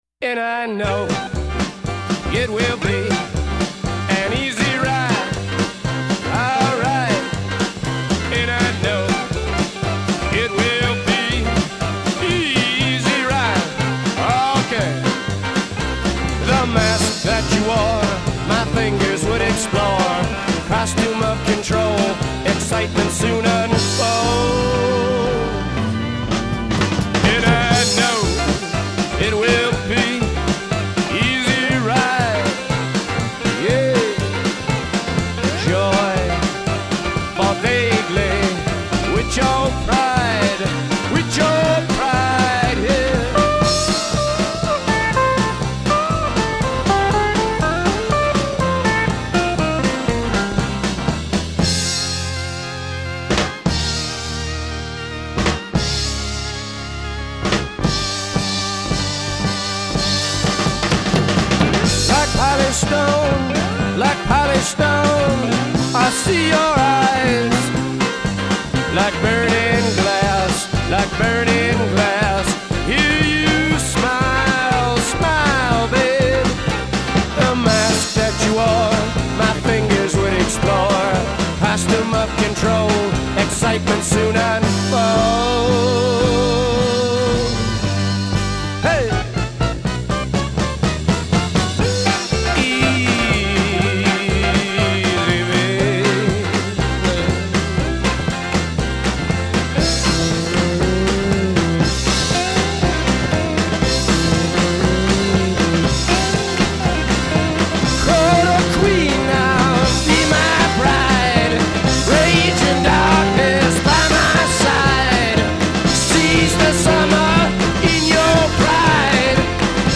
Rock Рок Рок музыка